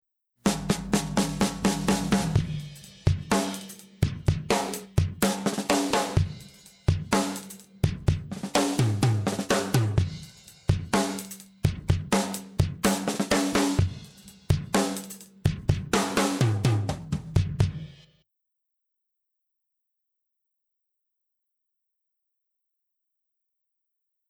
In this first example, I routed the kick and snare drum tracks to a second mix bus, the parallel compressor (“IIComp” as I call it – see the screen shot below,) and mixed the result with the dry signal heard above:
Note how the kick drum and snare drum have more body and punch while still sounding dynamic. (In practice, I wouldn’t mix the compressed signal quite so loud. I overdid it here to better illustrate the effect.)
tipIIComp-compressed.mp3